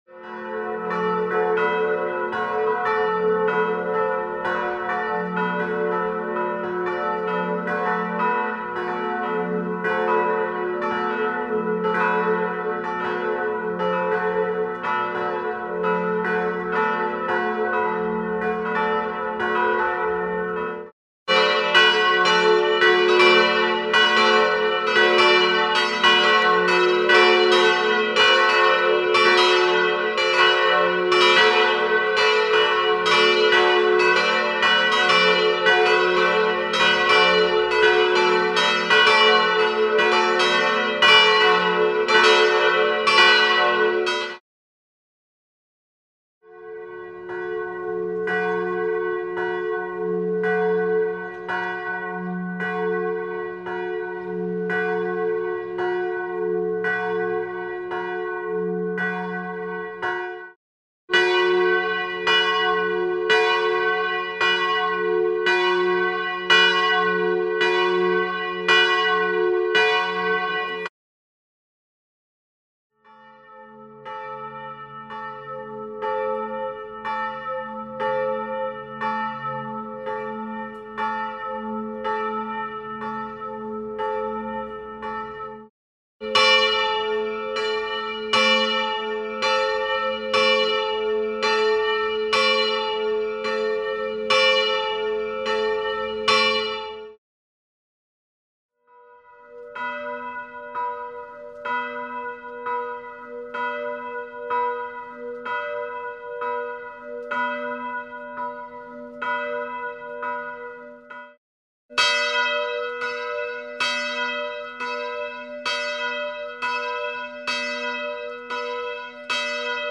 Das Glockengeläut klingt runder, harmonischer und auch weniger laut.
Hier hören Sie Aufnahmen des Glockengeläuts in einem «Nachher-Vorher»-Vergleich, zuerst das Gesamtgeläute, dann die einzelnen Glocken.
Dies resultiert in einem angenehmeren Klang.
Glockensanierung_ref_Kirche_Meggen.mp3